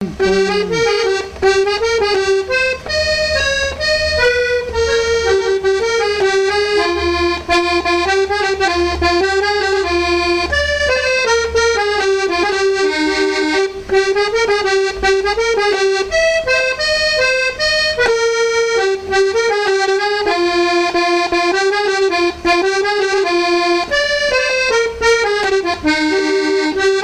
Localisation Aizenay
danse : java
Genre strophique